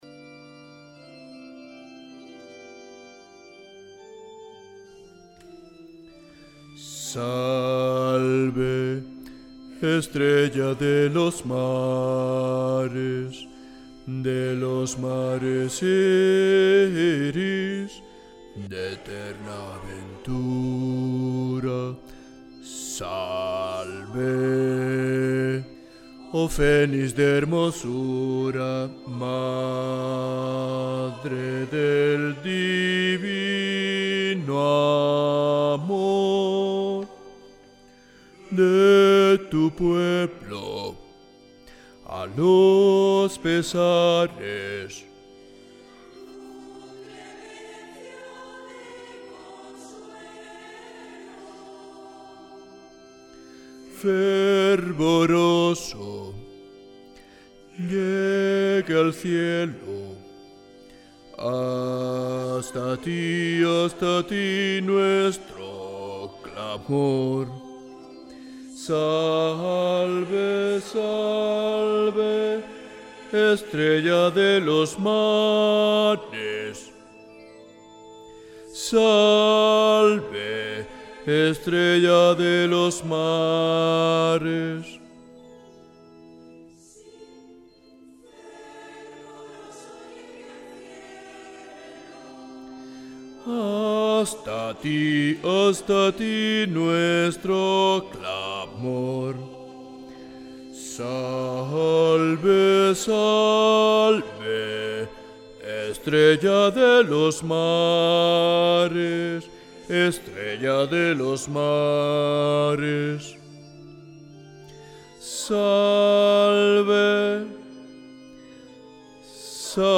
Musica SACRA Bajos
Salve-Marinera-Bajos.mp3